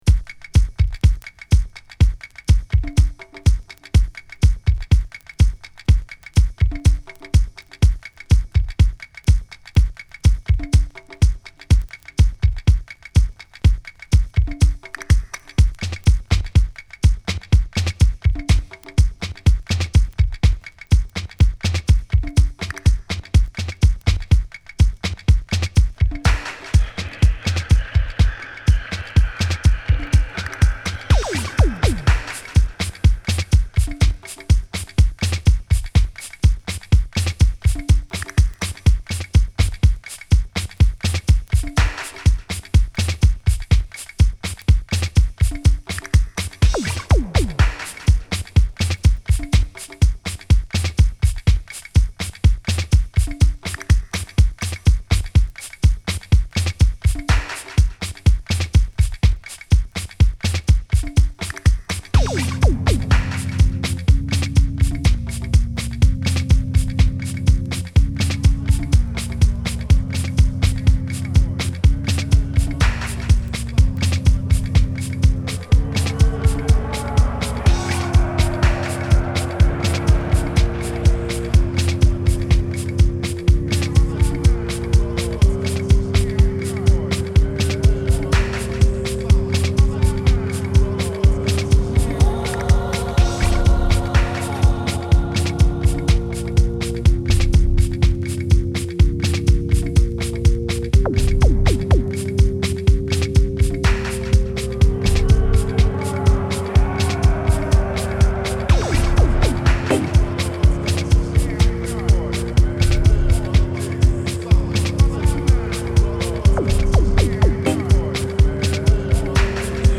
弾んだドラムに荘厳な雰囲気のヴォイスサンプルが淡く絡むディープなラテン・ビートダウンハウス